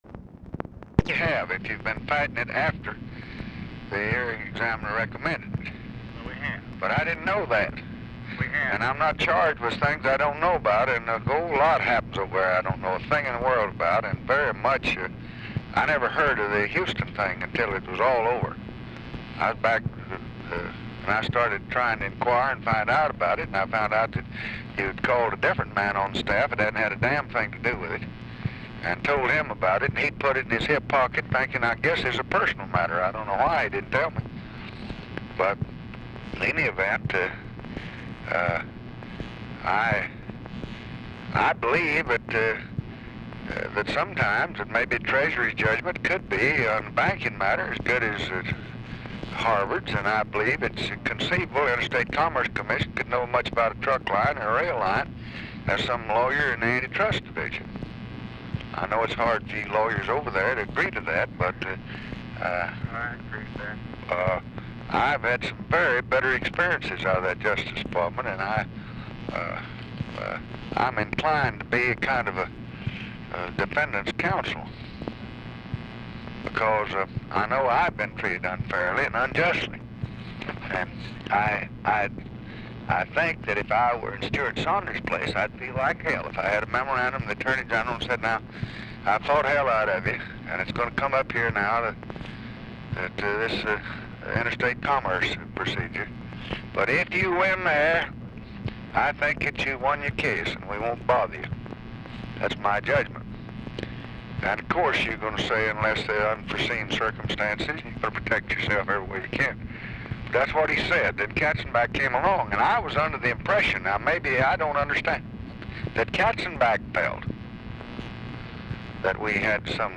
Telephone conversation # 11052, sound recording, LBJ and RAMSEY CLARK, 11/23/1966, 6:45PM | Discover LBJ
Format Dictation belt
Location Of Speaker 1 LBJ Ranch, near Stonewall, Texas